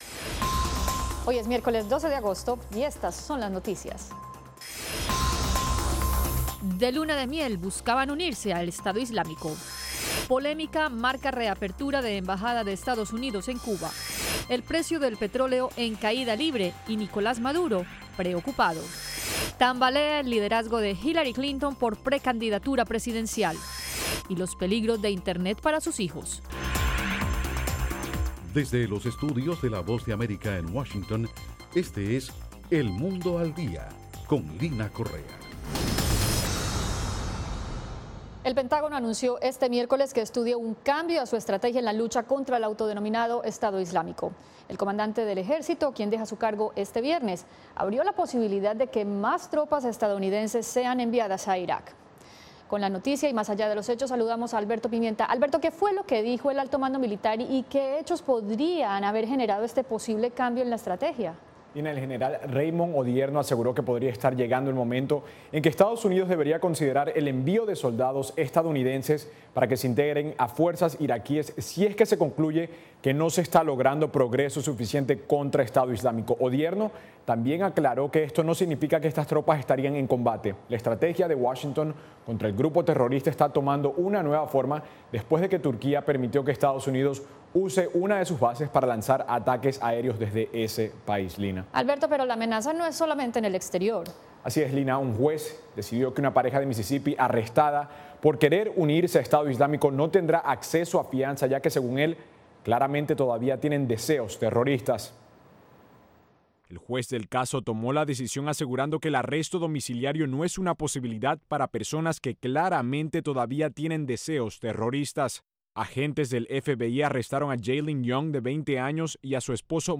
Una transmisión simultanea del noticiero de televisión “El mundo al día” en radio.